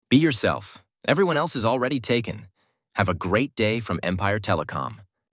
Empire Telecom offers its customers free, professionally recorded auto-attendant greetings and voicemail messages.
Male